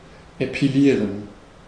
Ääntäminen
IPA: /epiˈliːʁən/ IPA: [ʔepʰiˈliːɐ̯n]